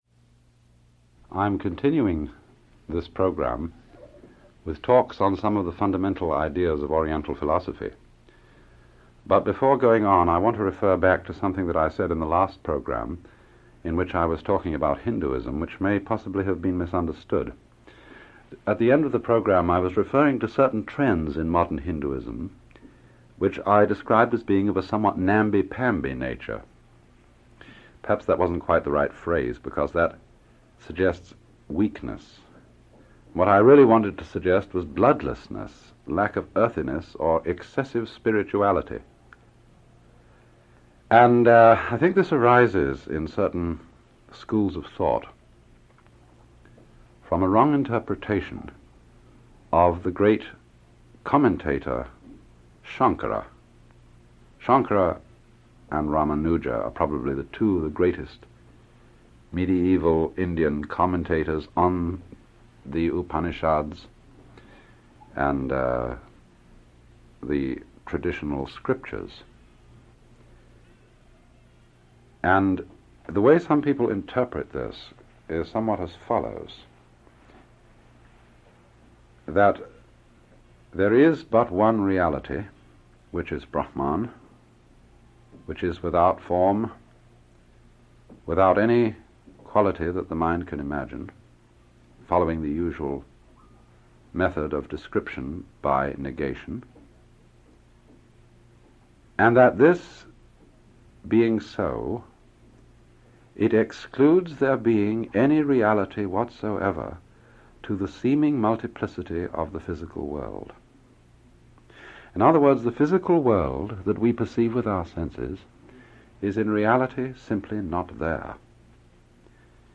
Alan-Watts-Early-Radio-Talks-08-Fundamental-Buddhism.mp3